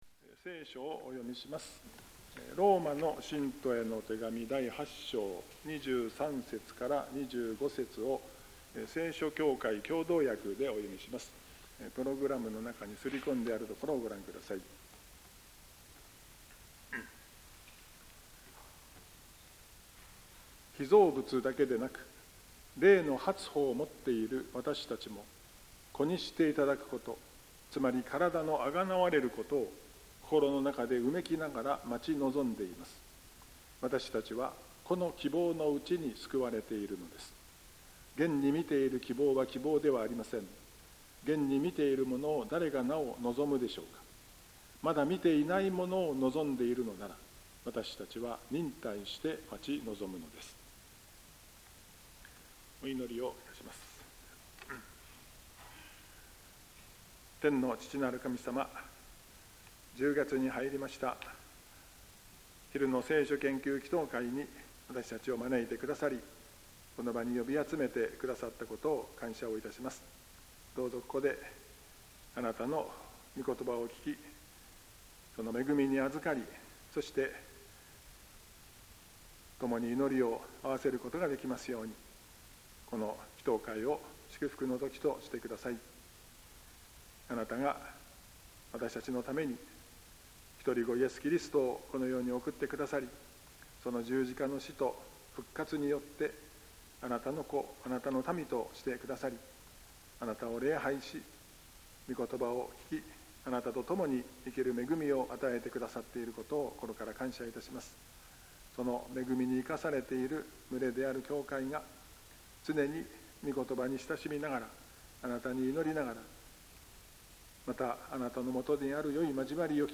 2025年10月の聖句についての奨励（10月1日 昼の聖書研究祈祷会）奨励「私たちは、この希望のうちに救われているのです。